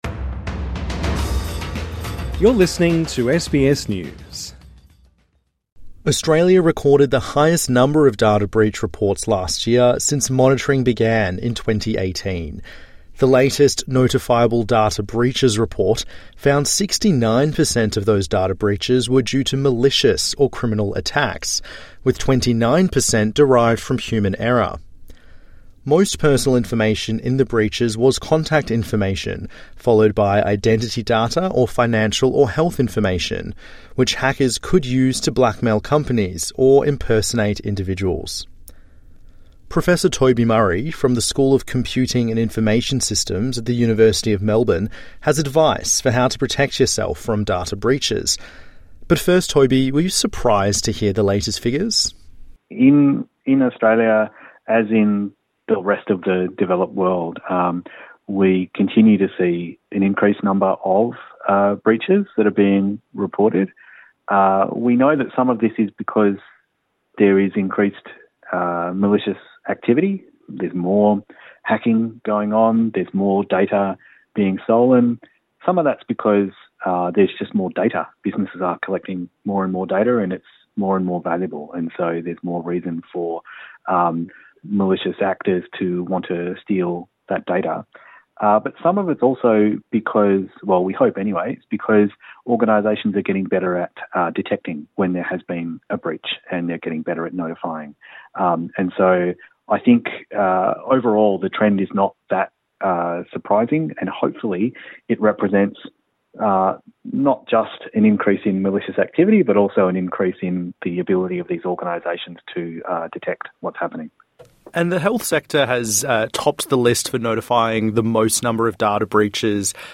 INTERVIEW: Record numbers of malicious data breaches recorded - how do you protect yourself?